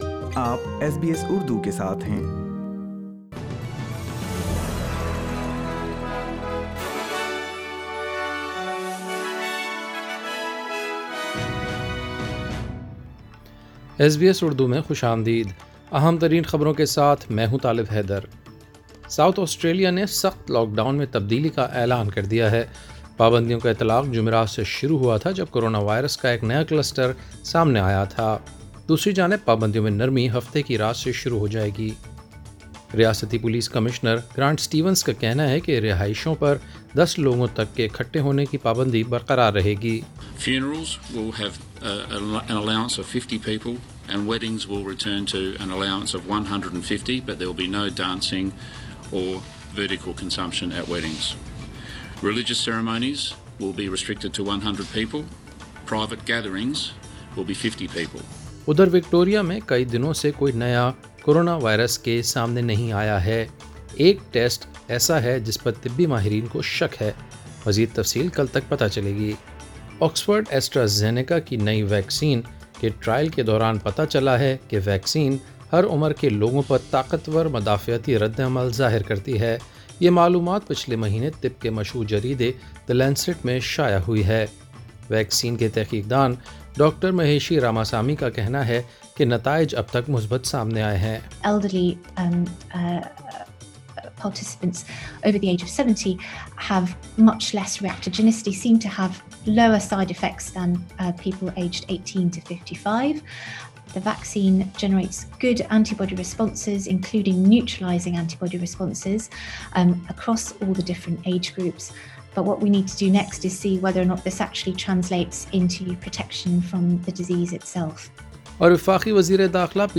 ایس بی ایس اردو خبریں 20 نومبر 2020